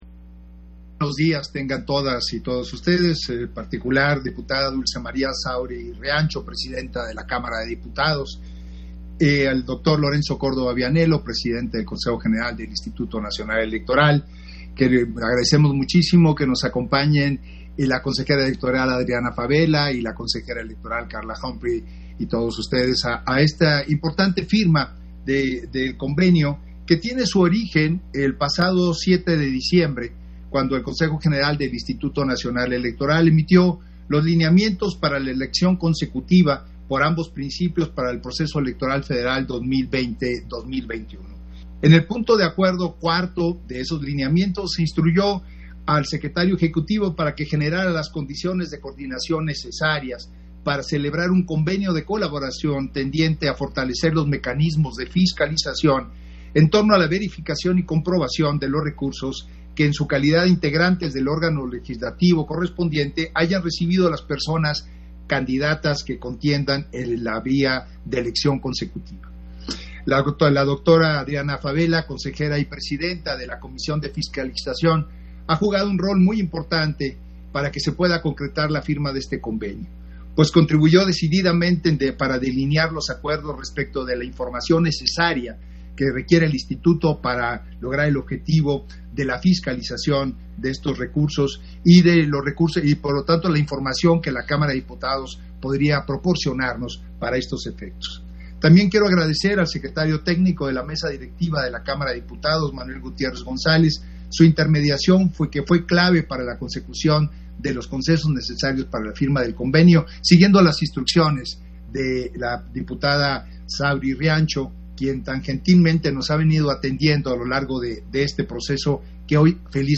Intervención de Edmundo Jacobo Molina, durante la firma del convenio con la Cámara de Diputados para mecanismos de fiscalización, verificación y comprobación de recursos de legisladoras/es que busquen elección consecutiva